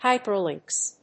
/ˈhaɪpɝlɪŋks(米国英語), ˈhaɪpɜ:lɪŋks(英国英語)/